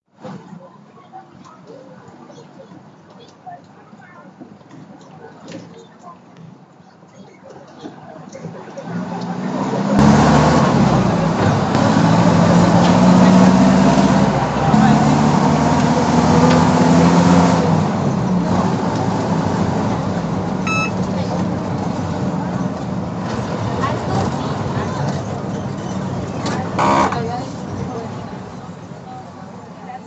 描述：人们，车辆，门，公共汽车噪音，波帕扬市中心，哥伦比亚。
Tag: 为其添加地理标记 城市 汽车 汽车